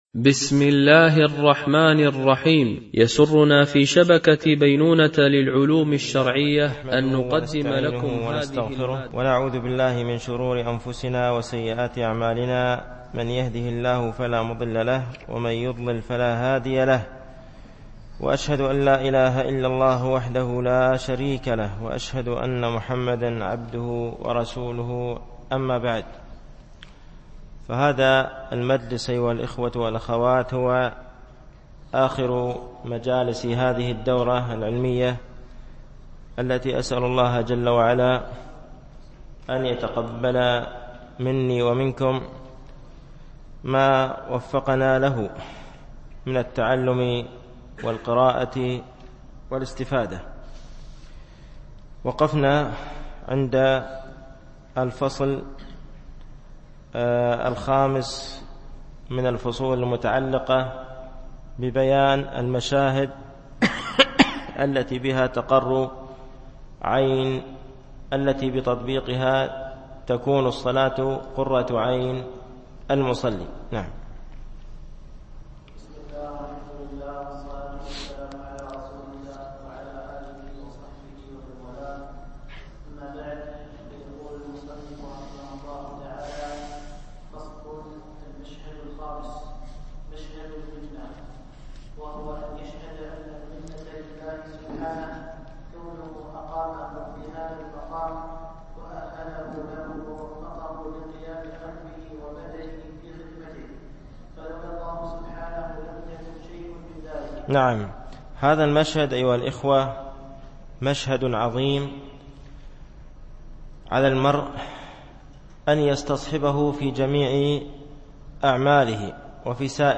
رسالة ابن القيم إلى أحد إخوانه - الدرس السابع والأخير